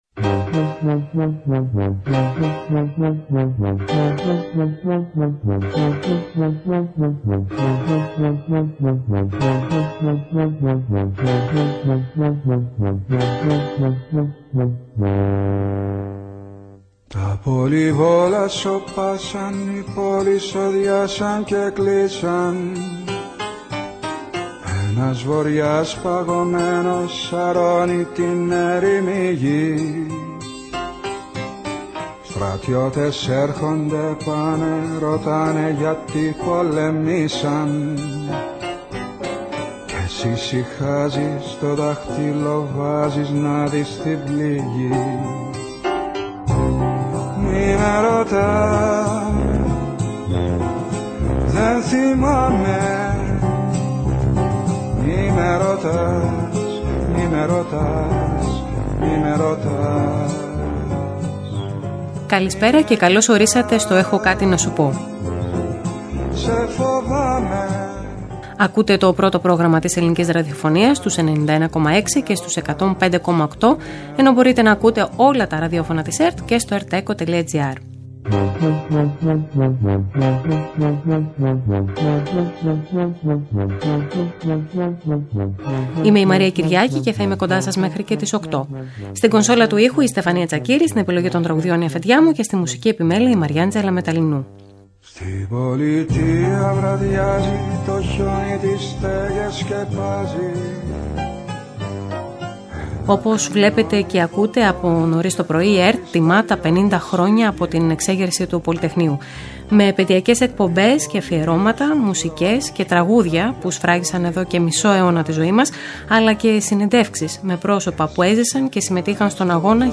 Κάθε Παρασκευή 7 με 8 το βράδυ, ένα τραγούδι γίνεται οδηγός για το κεντρικό θέμα σε κάθε εκπομπή. Για το ευ στο ζην, από συναισθήματα και εμπειρίες μέχρι πεποιθήσεις που μας κάνουν να δυσλειτουργούμε ή να κινητοποιούμαστε, έχουν κάτι να μας πουν ειδικοί σε επικοινωνία με ακροατές. Και ακόμη, δημοσιογράφοι, παραγωγοί, άνθρωποι των media, δημιουργοί, συντελεστές μιλούν για τις σειρές μυθοπλασίας που θα παρακολουθήσουμε φέτος από τις συχνότητες της ΕΡΤ, πάντα με οδηγό ένα τραγούδι.